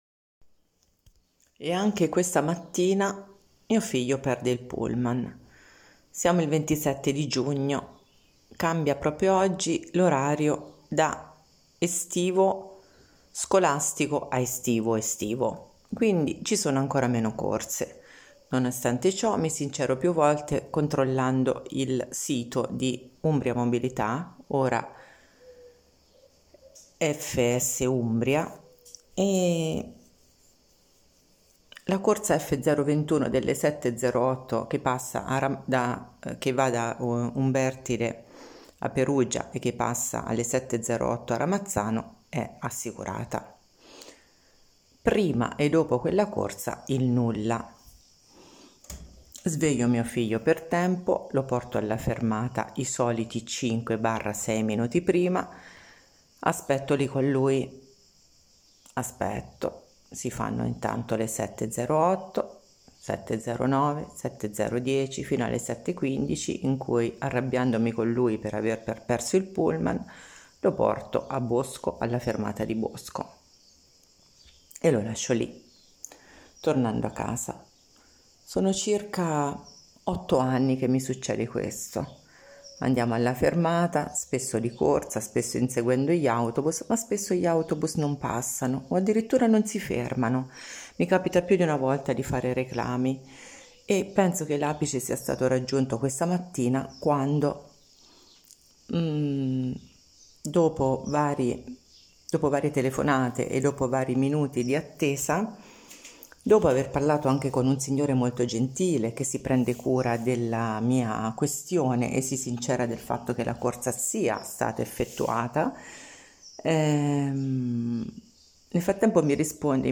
Questa la testimonianza di una residente in una frazione di Perugia, Ramazzano, di cui Cronache Umbre ha verificato nome, cognome, affidabilità e della quale proprio per questo condivide la denuncia.